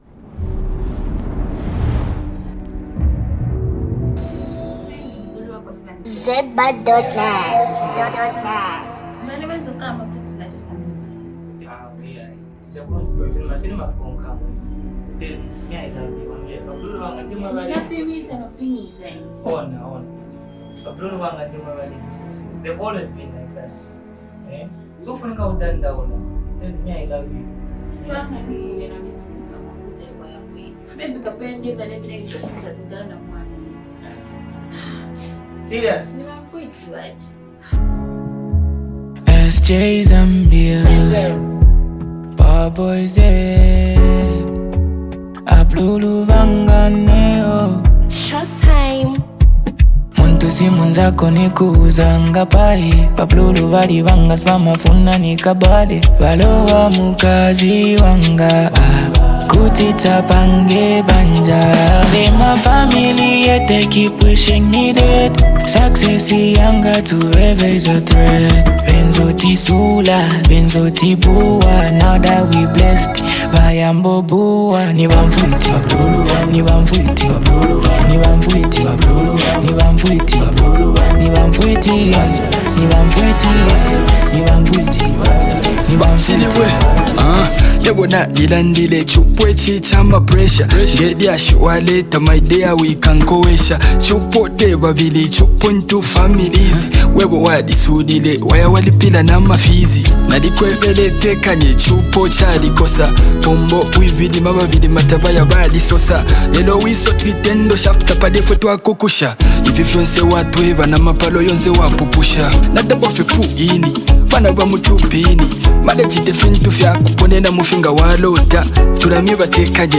Genre: Zambian Music